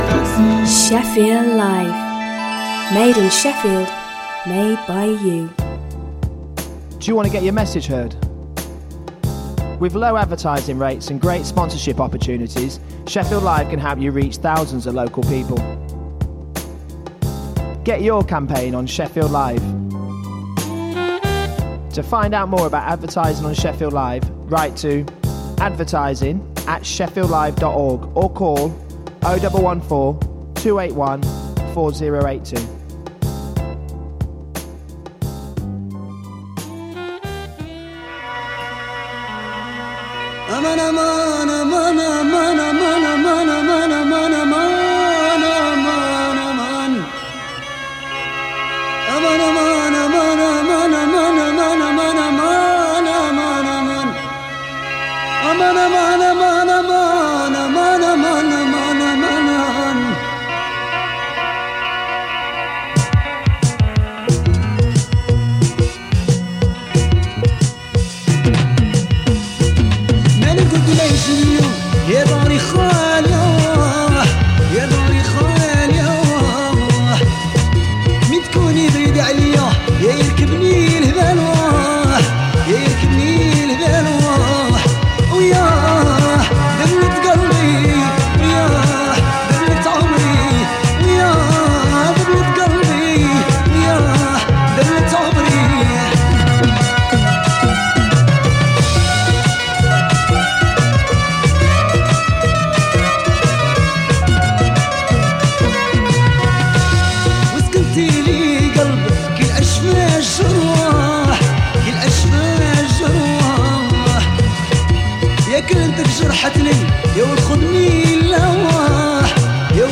Jolly music, news, chat and regular prize giveaways!